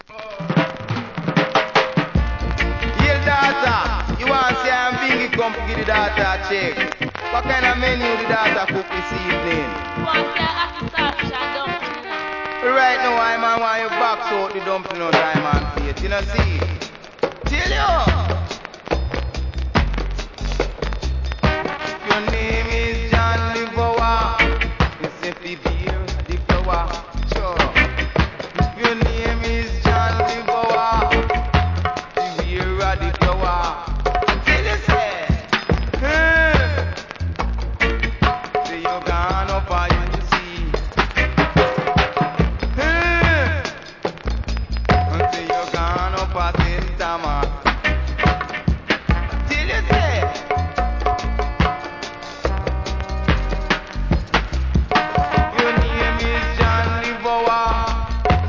REGGAE
DeeJay CUT!!